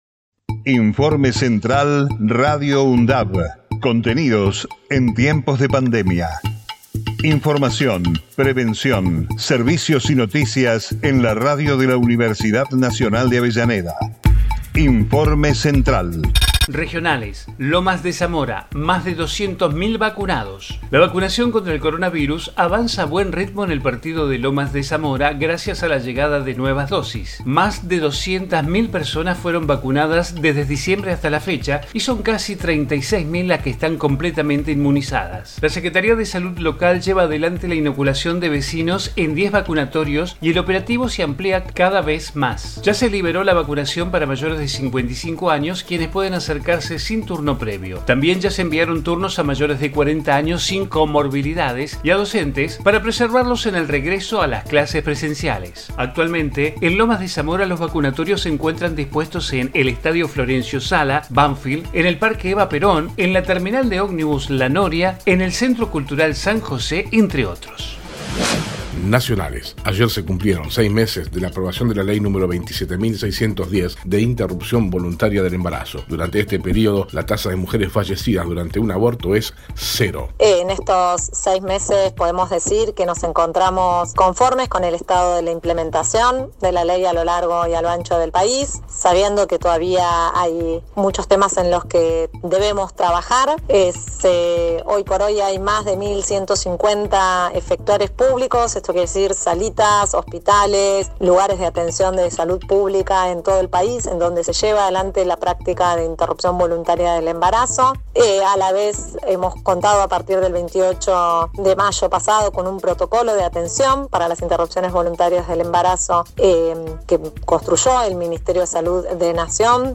COVID-19 Informativo en emergencia 01 de JULIO 2021 Texto de la nota: Informativo Radio UNDAV, contenidos en tiempos de pandemia. Información, prevención, servicios y noticias locales, regionales y universitarias.